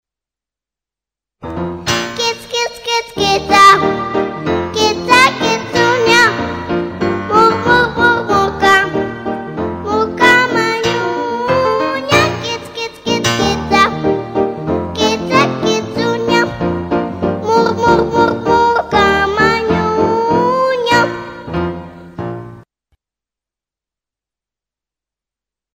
говорящие коты